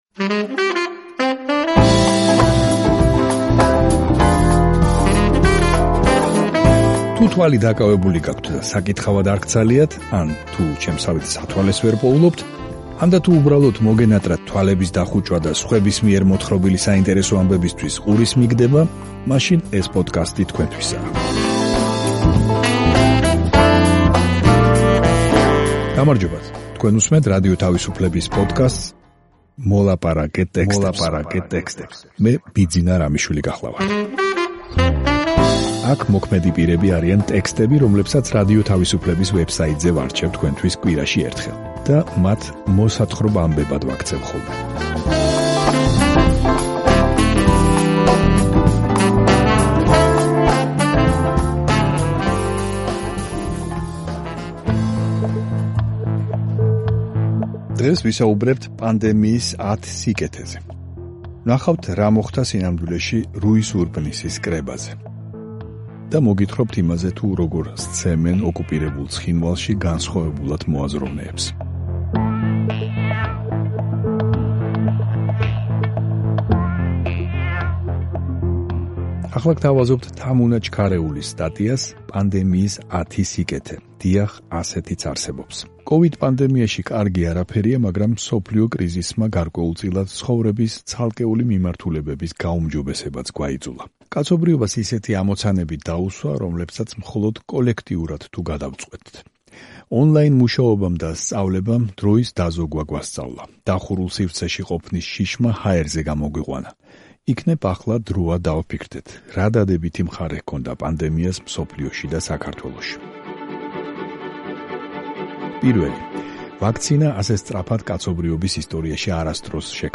თუ თვალი დაკავებული გაქვთ და საკითხავად არ გცალიათ, ან თუ სათვალეს ვერ პოულობთ, ანდა, თუ უბრალოდ მოგენატრათ თვალების დახუჭვა და სხვების მიერ მოთხრობილი საინტერესო ამბებისთვის ყურის მიგდება, მაშინ ეს პოდკასტი თქვენთვისაა.